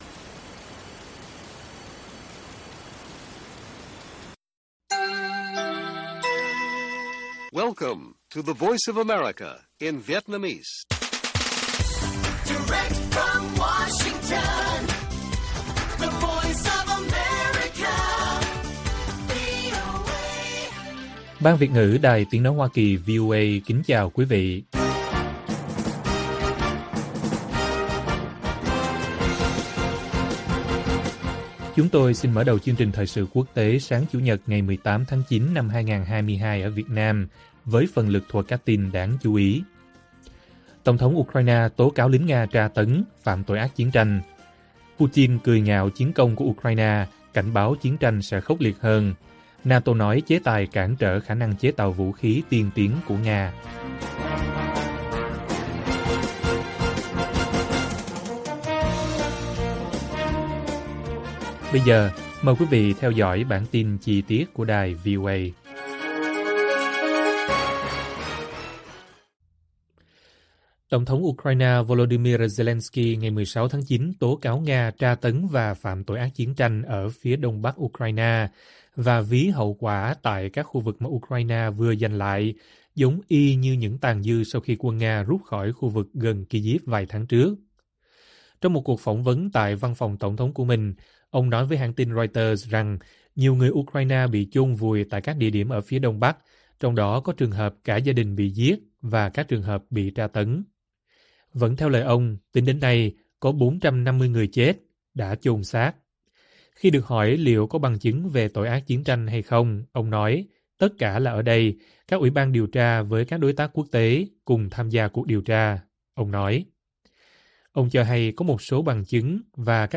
Tổng thống Ukraine tố Nga tra tấn, phạm tội ác chiến tranh - Bản tin VOA